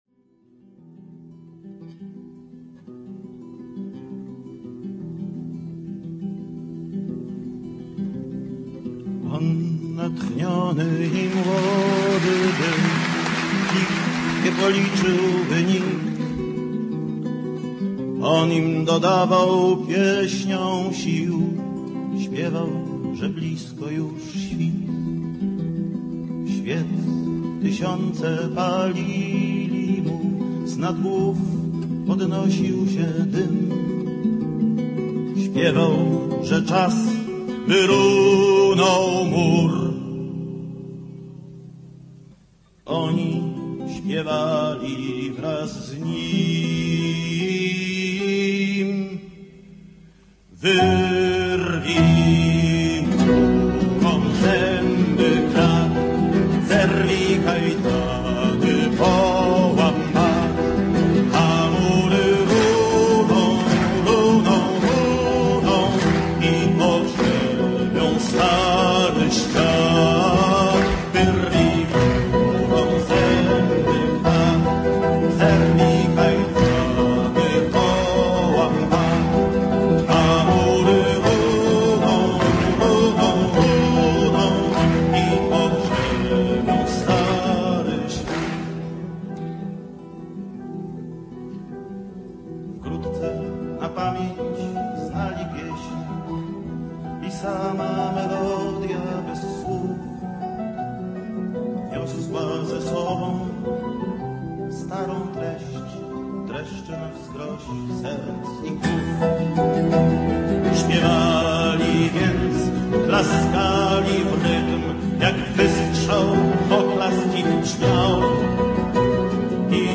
Обратите внимание на реакцию слушателей: